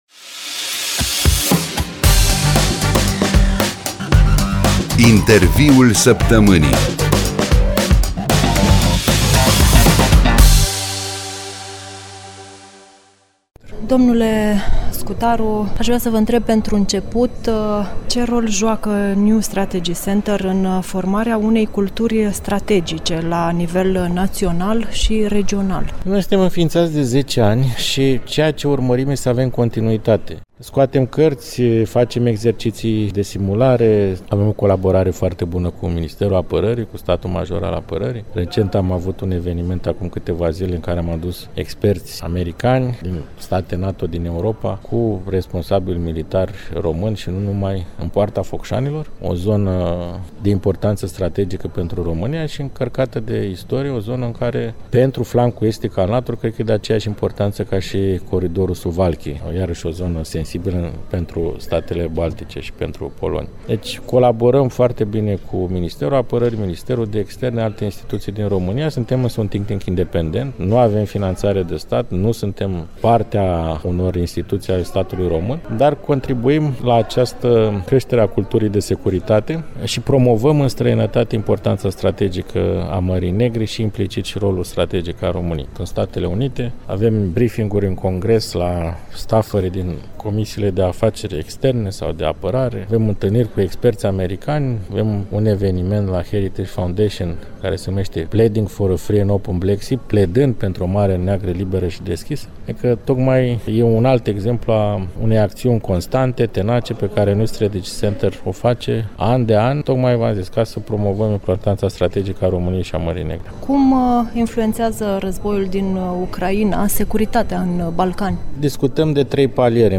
Interviul săptămânii luni, 30 martie 2026, 12:37